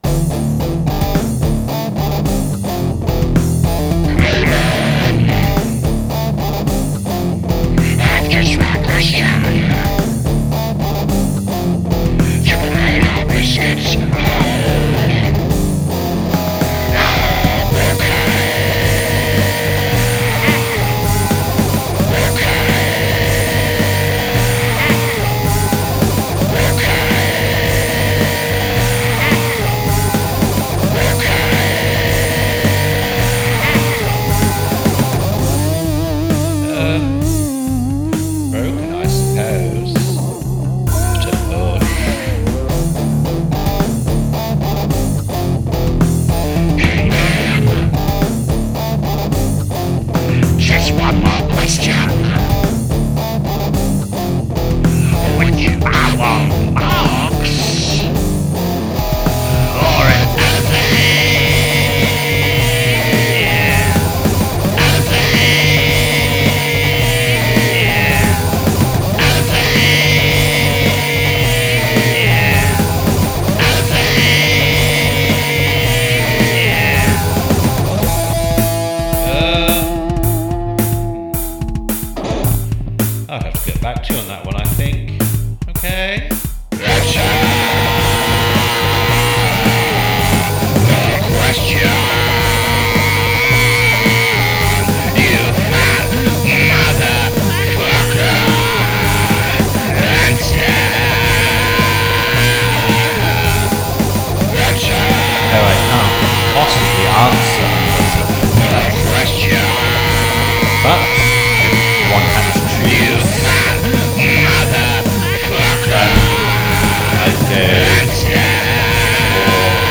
except intermittent growl
some music for the soul